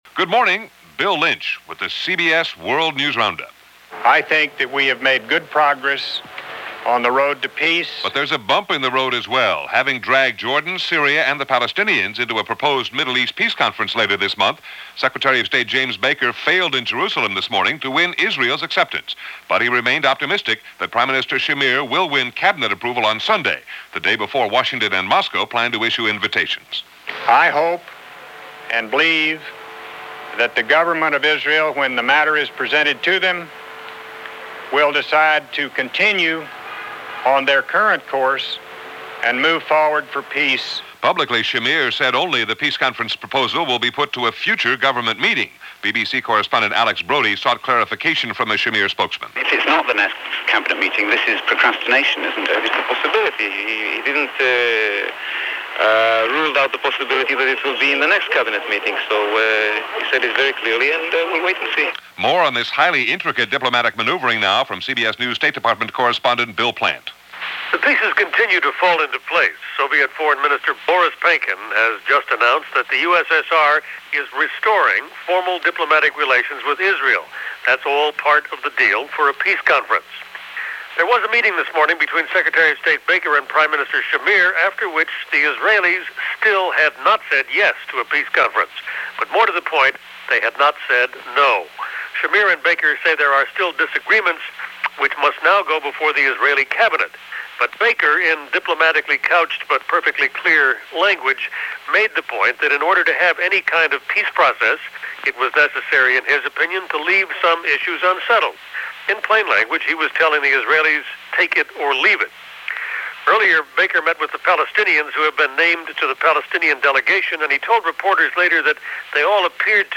October 8, 1991 – CBS World News Roundup – Gordon Skene Sound Collection –
All that, and a lot more going on, this October 18, 1991 as reported by The CBS World News Roundup.